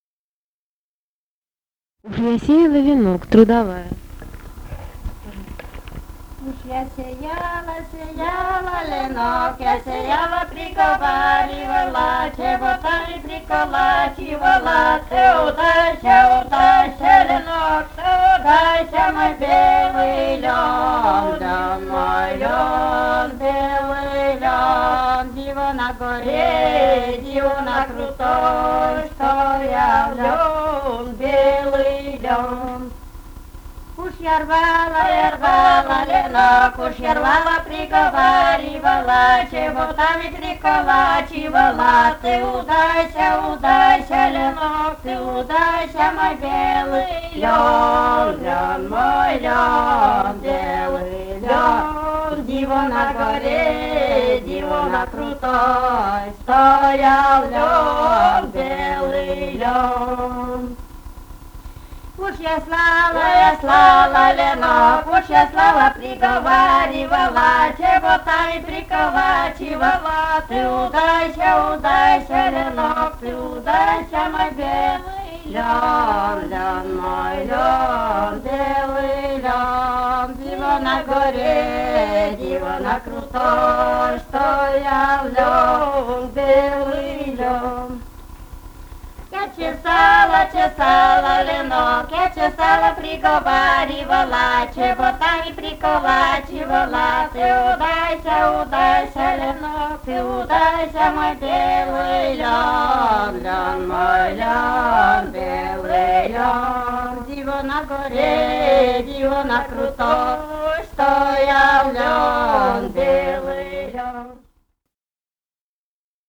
Этномузыкологические исследования и полевые материалы
«Уж я сеяла, сеяла ленок» (хороводная игровая).
Пермский край, д. Пепеляево Очёрского района, 1968 г. И1077-13